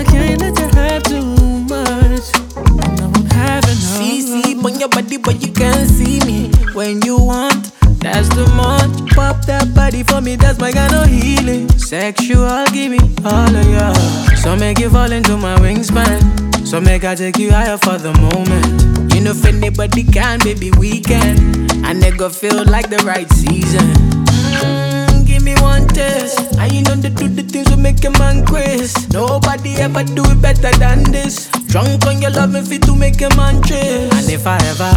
Жанр: Африканская музыка
# Afrobeats